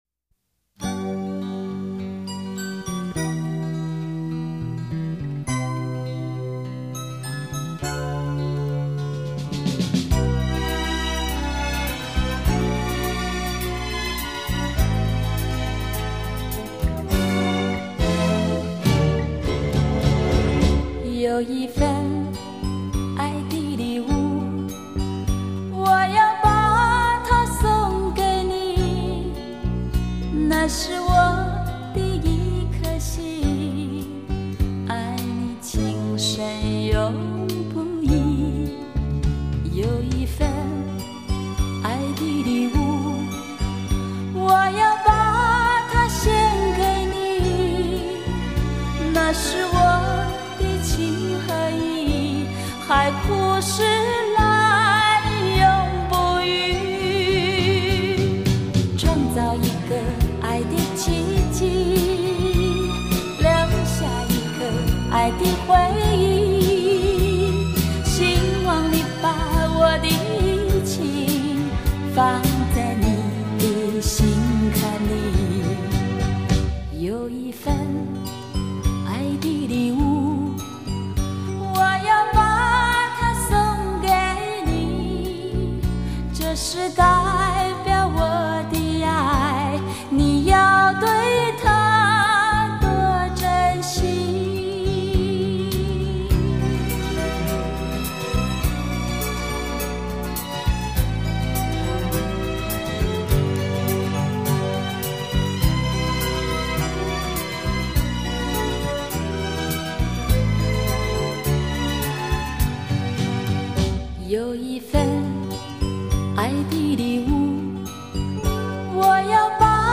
特别是这些歌曲经过重新配器，既保留原曲的韵味，又体现了当时的最高质量录音，即便在今日听来依旧绕梁三日、娓娓动听。
她的声音亲切而温柔